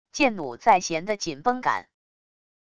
箭弩在弦的紧绷感wav音频